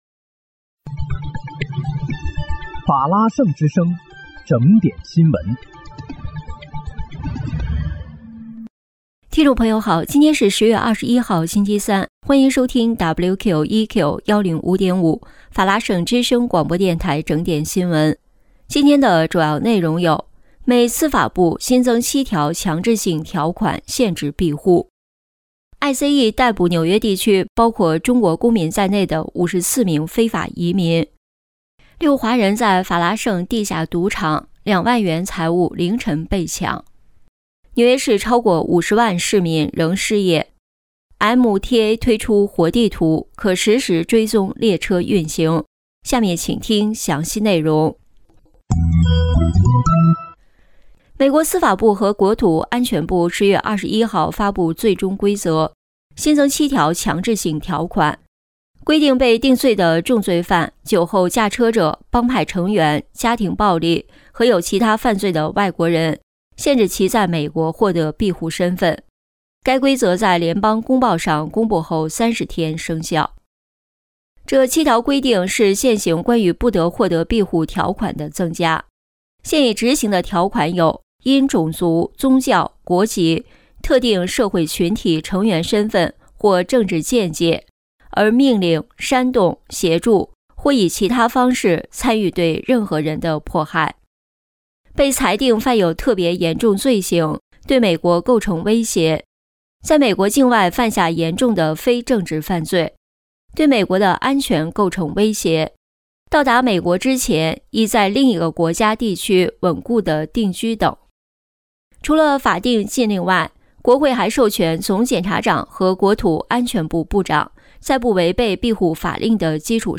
10月21日（星期三）纽约整点新闻